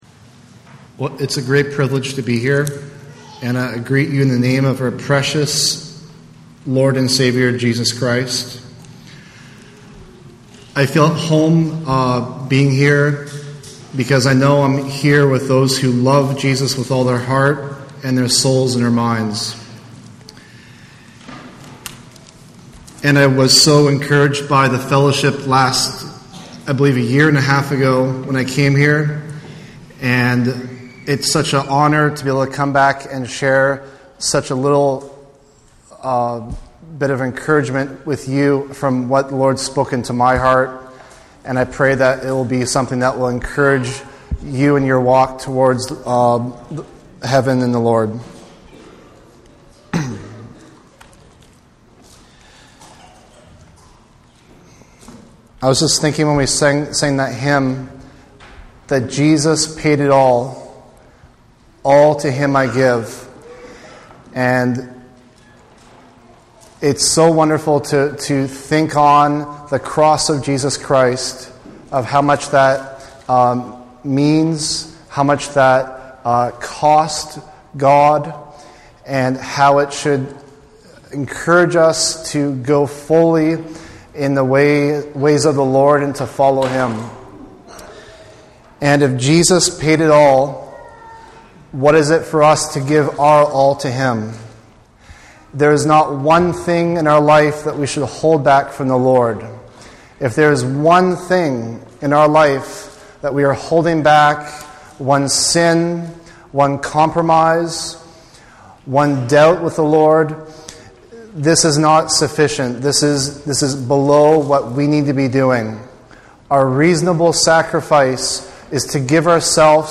Sunday Morning Sermon Passage: Ephesians 4:1-5:33 Service Type